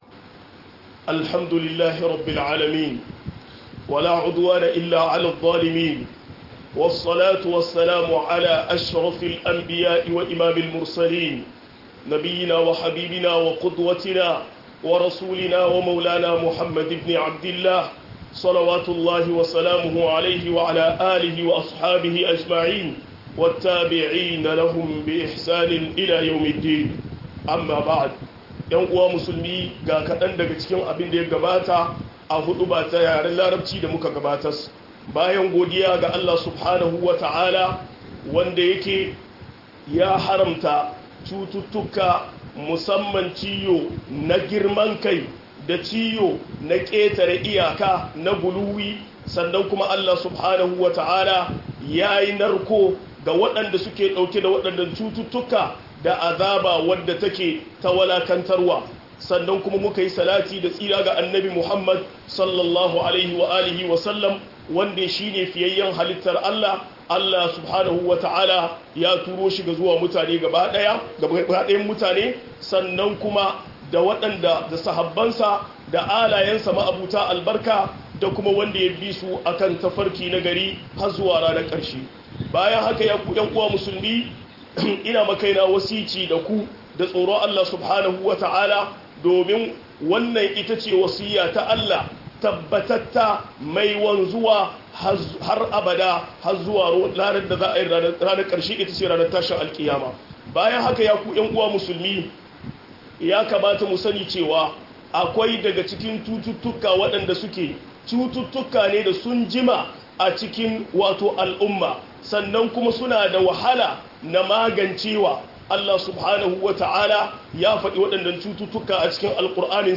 Girman kai da ƙetare iyaka - MUHADARA